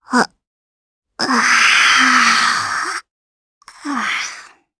Gremory-Vox_Yawn_jp.wav